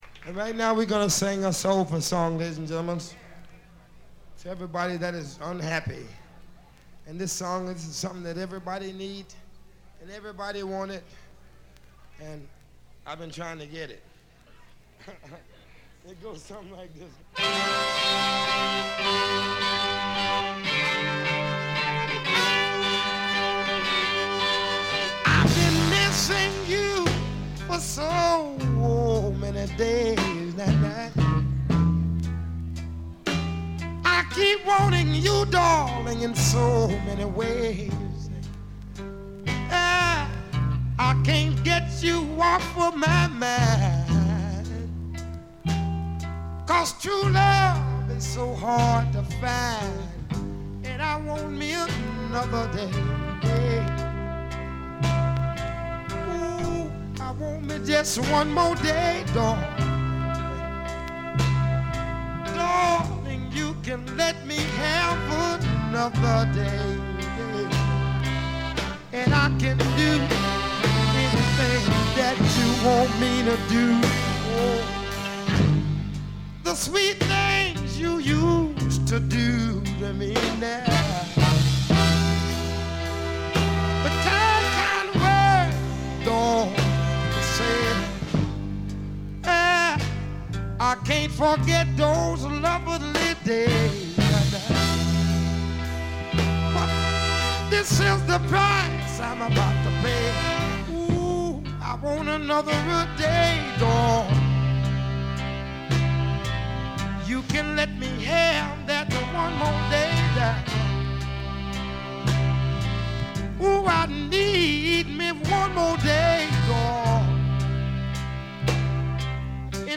散発的なプツ音が少々。
全員一丸となってペース配分も考えずに疾走しきった感がしっかり伝わってくる素晴らしいライヴ音源です。
試聴曲は現品からの取り込み音源です。
Guitar
Bass
Drums
Tenor Saxophone
Trombone
Trumpet